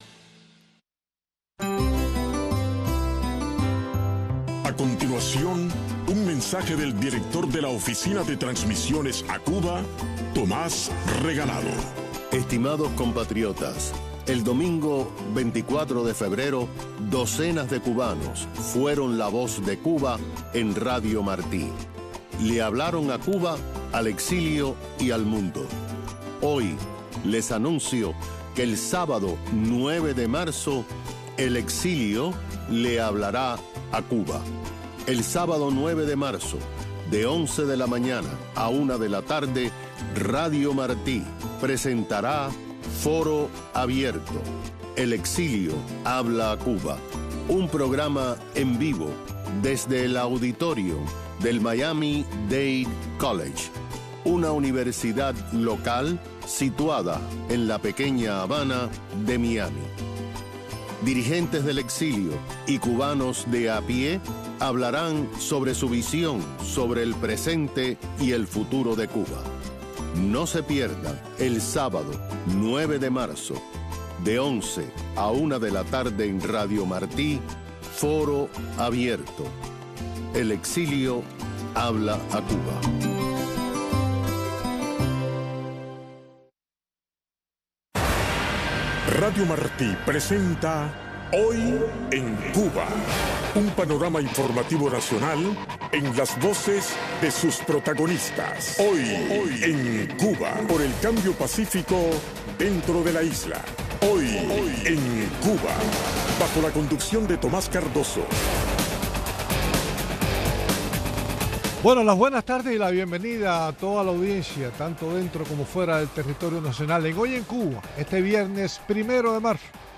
Presionan a campesinos cubanos para que vendan sus cosechas al estado, de este tema hablamos con tres agricultores y un periodista independiente.